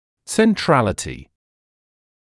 [sen’trælətɪ][сэн’трэлэти]центрированность; центральная роль; сосредоточенность